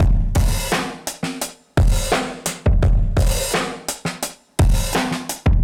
Index of /musicradar/dusty-funk-samples/Beats/85bpm/Alt Sound
DF_BeatB[dustier]_85-02.wav